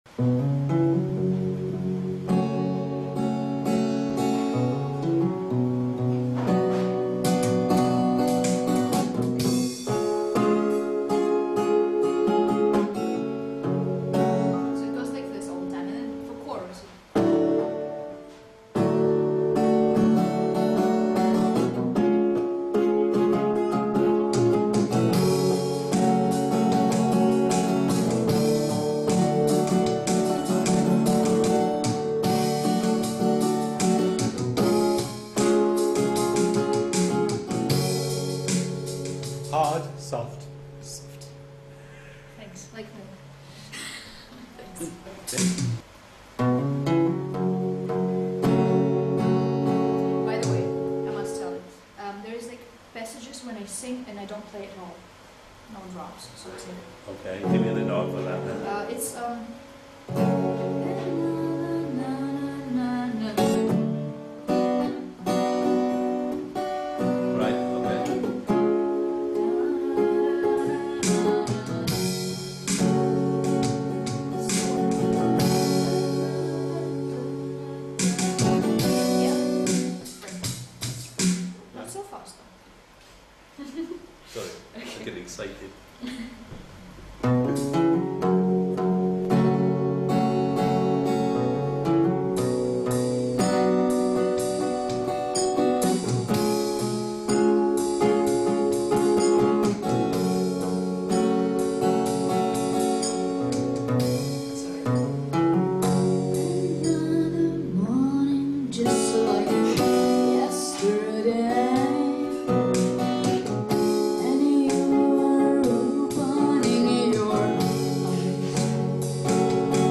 in the Chattery, Uplands, Swansea smile
GET_UP_original_REHEARSAL.mp3